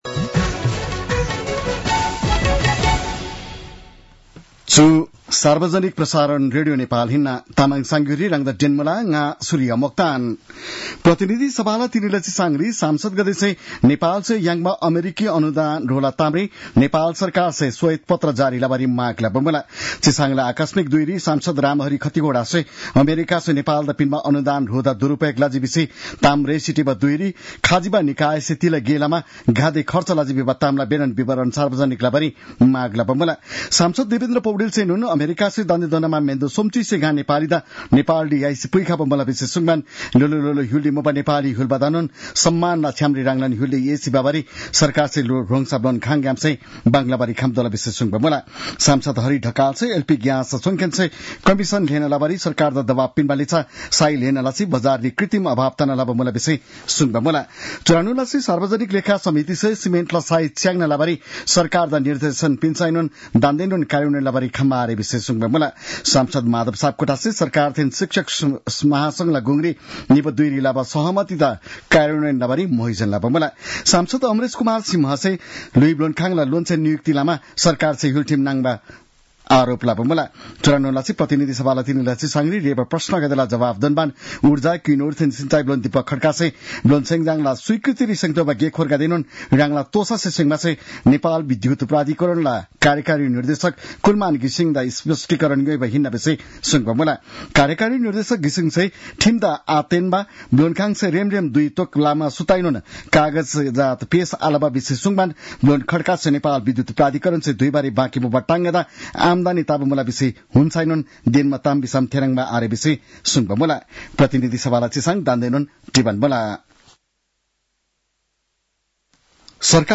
तामाङ भाषाको समाचार : २३ फागुन , २०८१